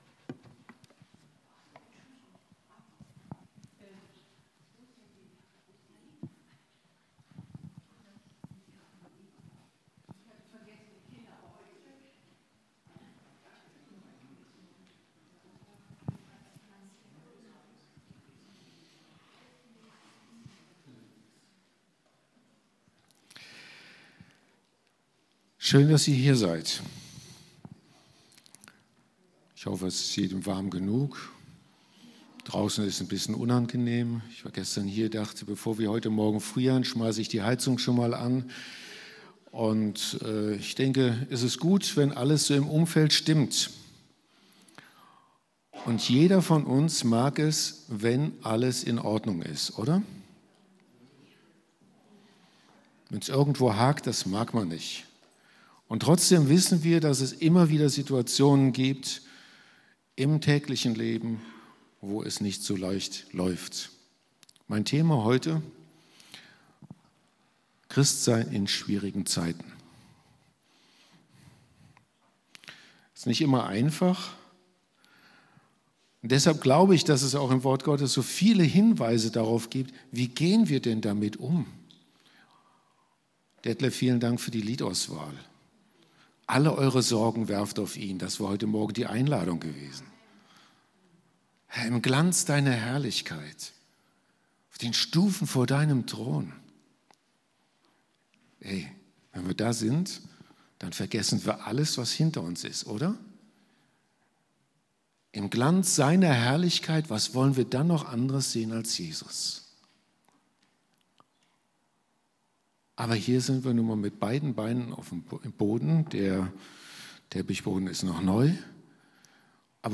Genre: Predigt.